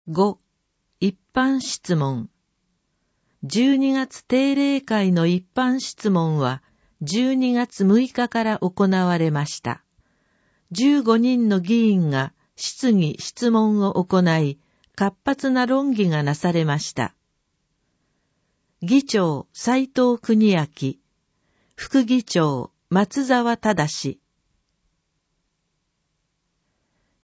「Windows Media Player」が立ち上がり、埼玉県議会だより 180号の内容を音声（デイジー版）でご案内します。